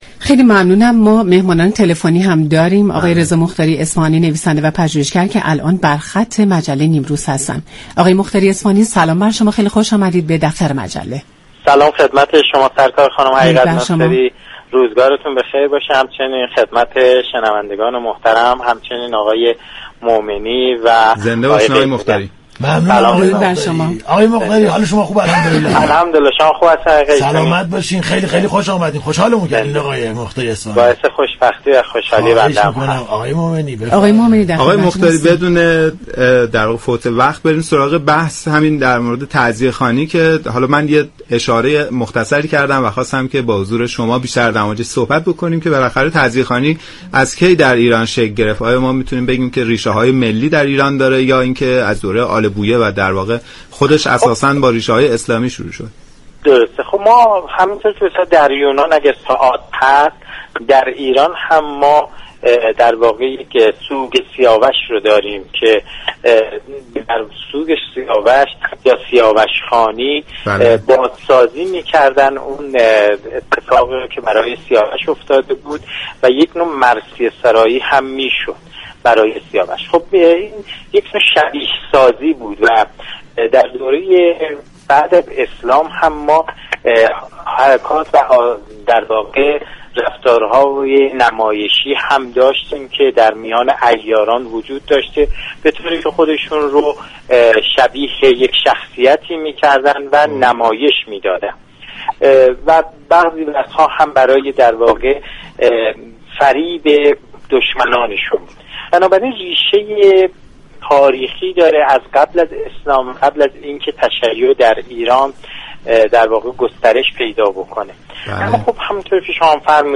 كارشناس و پژوهشگر تاریخ درباره ی تاریخ تعزیه در ایران گفتگو كرد .